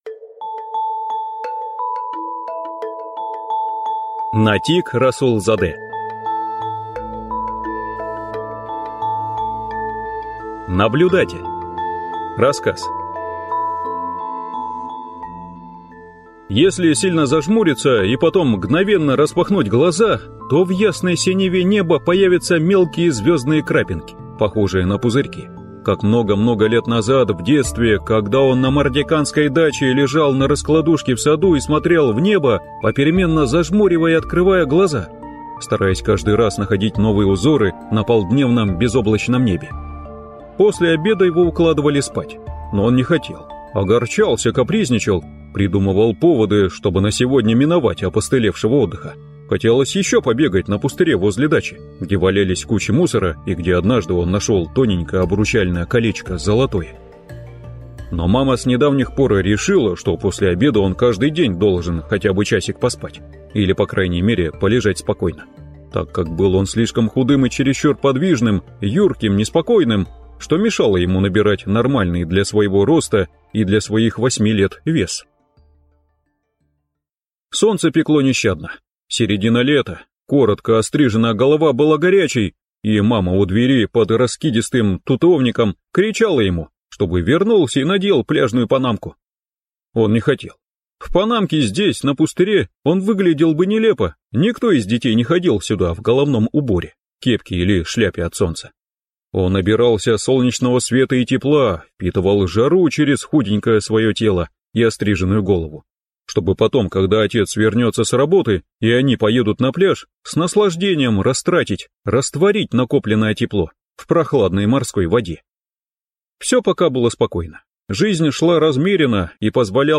Аудиокнига Наблюдатель | Библиотека аудиокниг
Прослушать и бесплатно скачать фрагмент аудиокниги